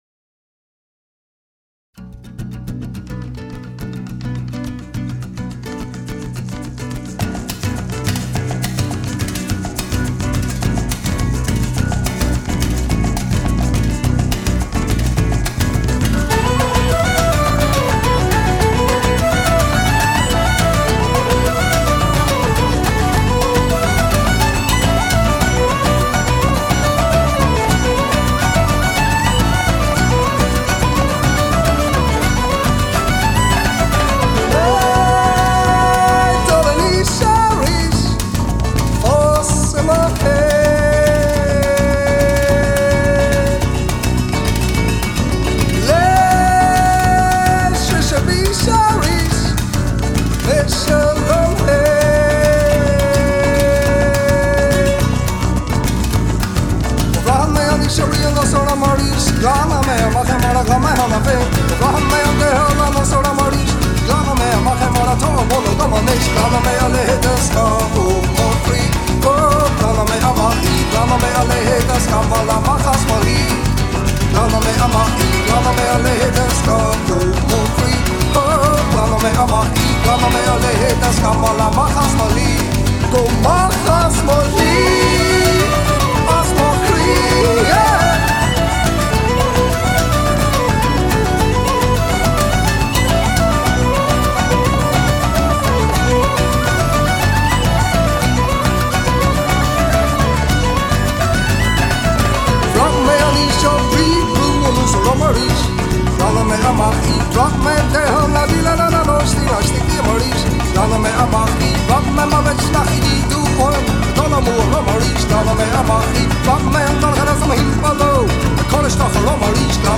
Often defined as contemporary Irish World music